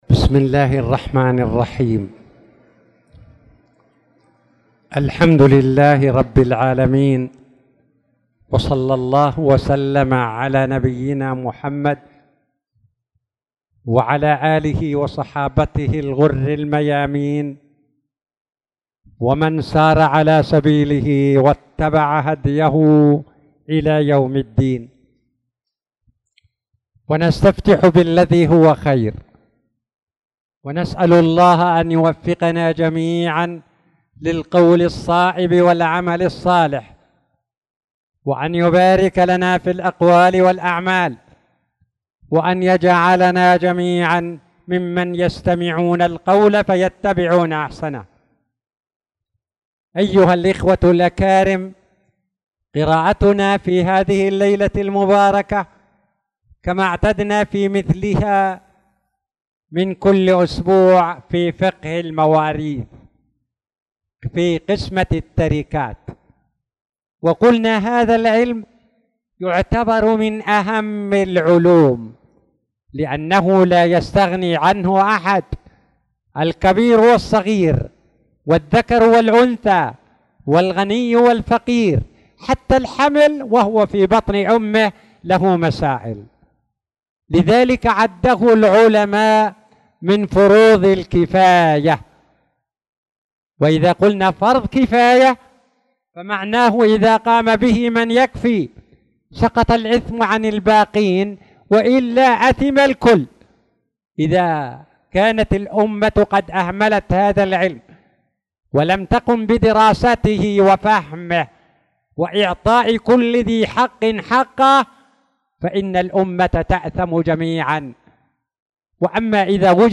تاريخ النشر ٢٧ شوال ١٤٣٧ هـ المكان: المسجد الحرام الشيخ